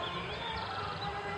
Southern House Wren (Troglodytes musculus)
Province / Department: Tucumán
Location or protected area: Cerro San Javier
Condition: Wild
Certainty: Recorded vocal